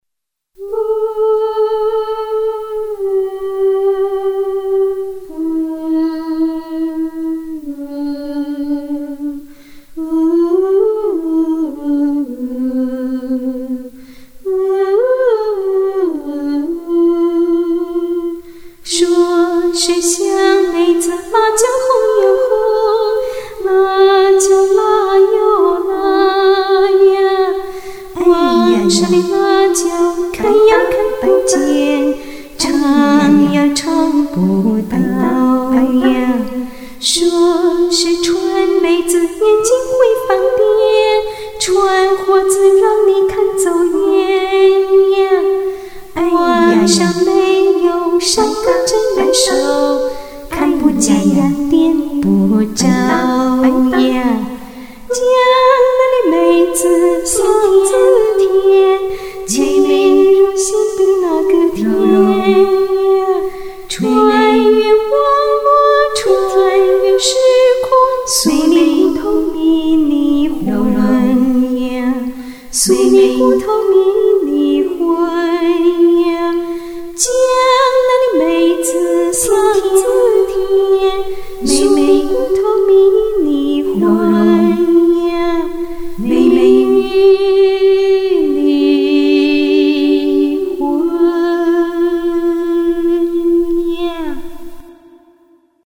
不能被个伴奏勒S啊，俺想起了人声伴奏了，这不这首《迷魂汤》就诞生在火线战场上了，我想以后我再来听，肯定会觉得很幼稚的。
和声伴奏配得绝了